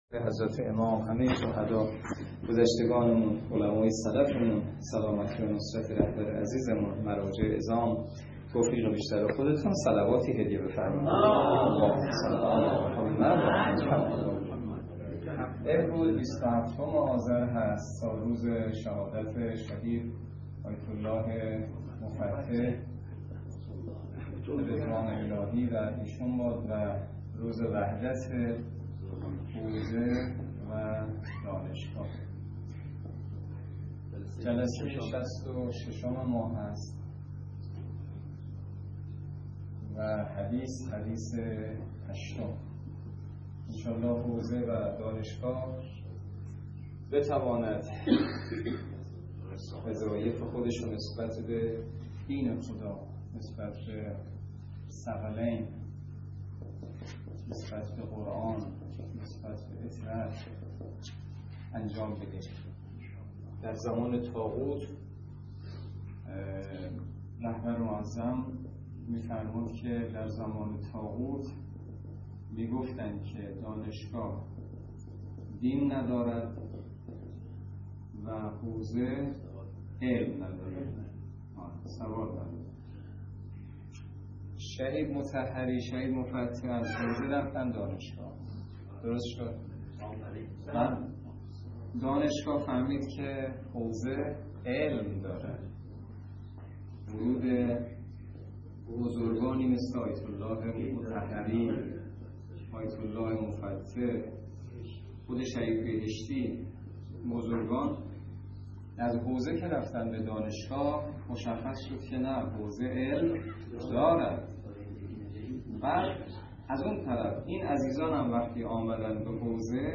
درس فقه الاجاره نماینده مقام معظم رهبری در منطقه و امام جمعه کاشان - سال سوم جلسه شصت و شش